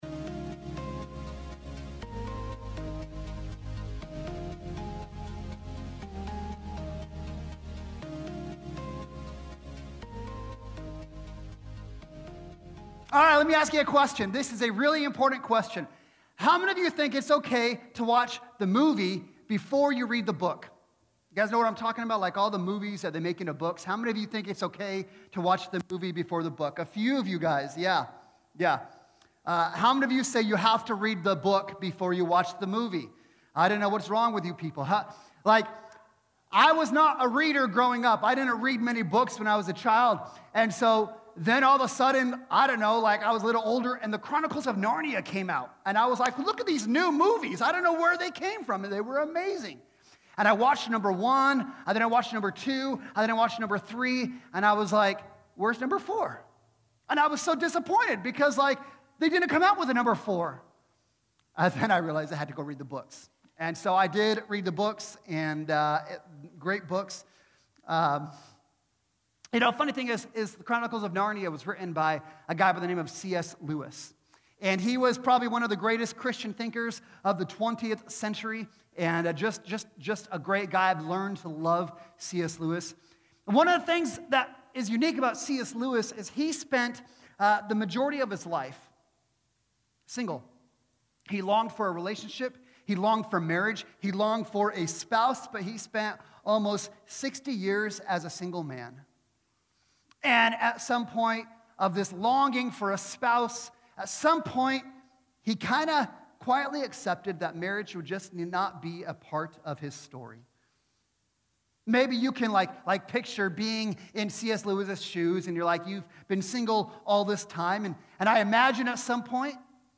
Weekly sermons from Restoration Church of Yakima